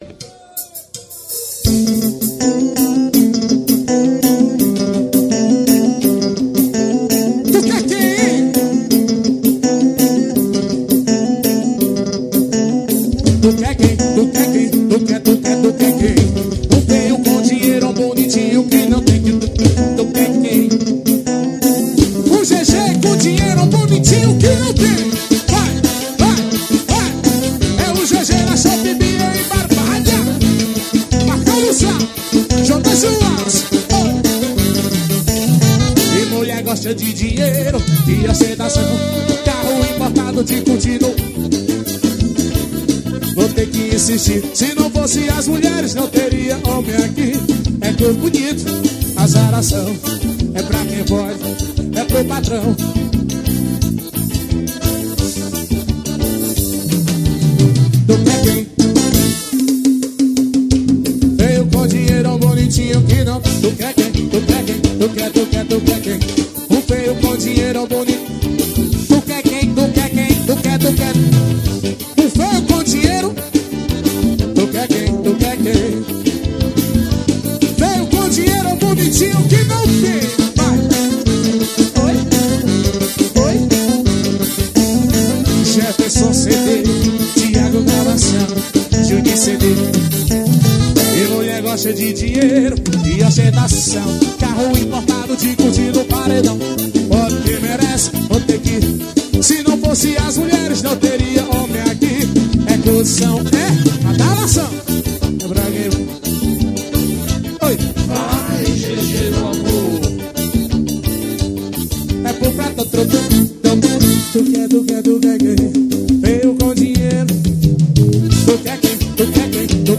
Composição: forro.